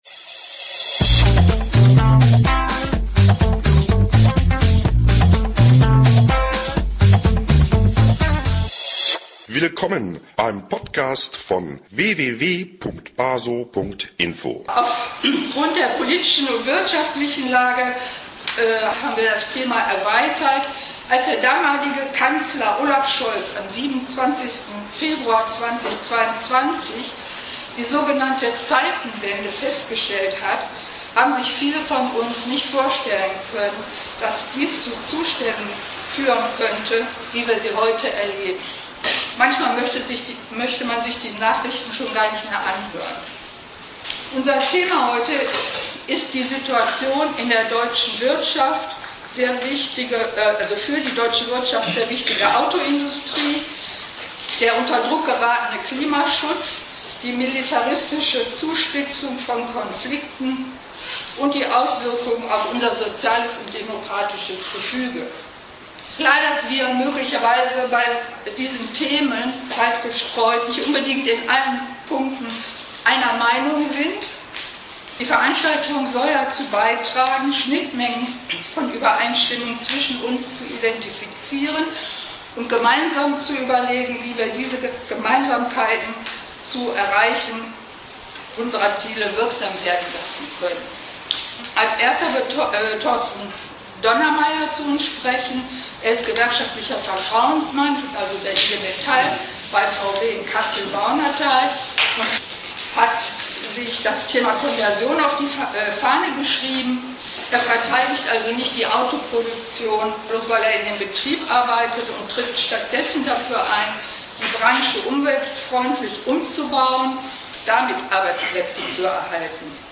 Von Betroffenen, Gewerkschafter*innen, Aktivisten erfahren wir hier aus erster Hand , was in der Industrie los ist, was das mit der von uns verlangten Kriegstüchtigkeit zu tun hat, und was dies für den Klimaschutz bedeutet.
Die Lenker von Wirtschaft und Staat verordnen Umrüstung zur Aufrüstung, Abschied vom Klimaschutz, Wettlauf um Rohstoffe - und - mehr und länger arbeiten. Das wird hier zum Thema gemachen: Eine Veranstaltung vom 26. Juni 2025 um 18:30 in Wuppertal Von Betroffenen, Gewerkschafter*innen, Aktivisten werden wir aus erster Hand erfahren, was in der Industrie los ist, was das mit der von uns verlangten K